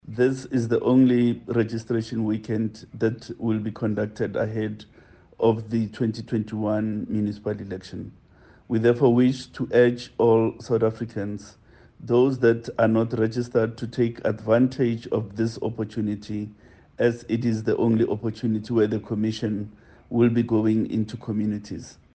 Chief electoral officer, Sy Mamabolo says, due to the commission’s finances, there would only be one voter registration weekend.